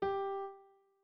01_院长房间_钢琴_13.wav